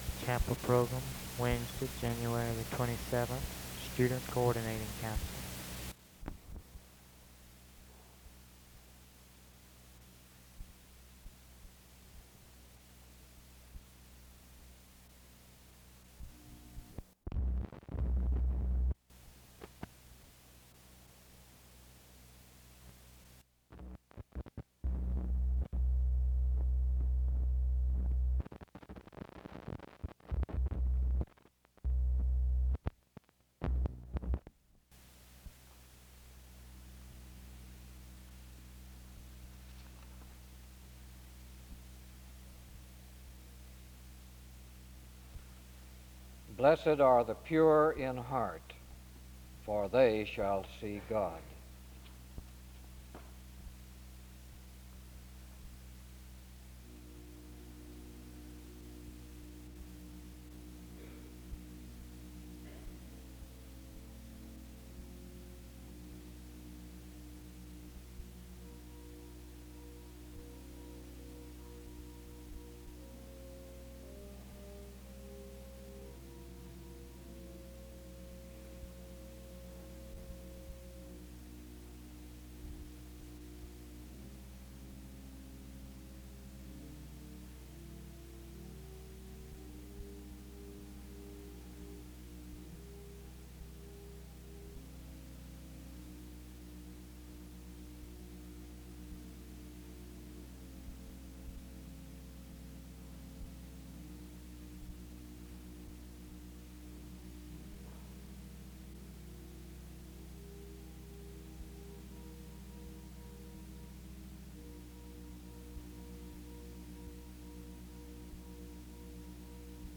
Music plays from 0:46-2:05.
Music plays from 5:06-8:00.
SEBTS Chapel and Special Event Recordings SEBTS Chapel and Special Event Recordings